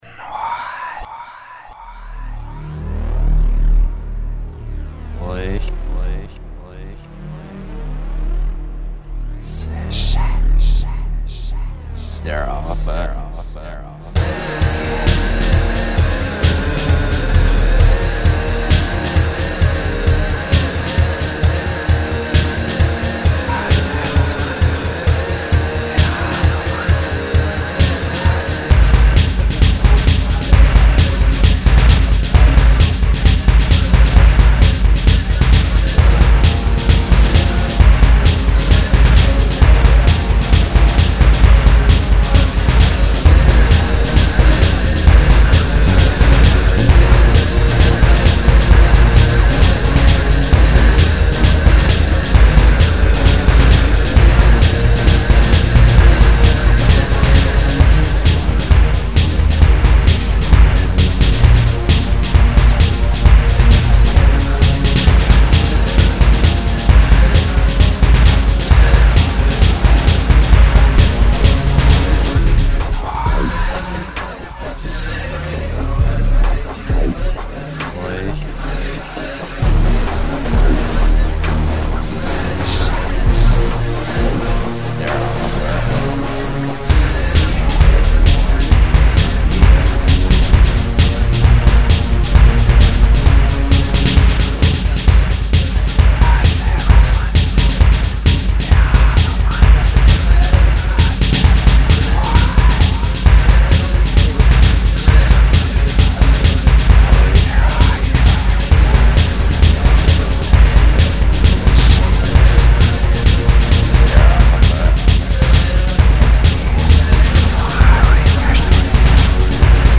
4:12 min [132 BPM]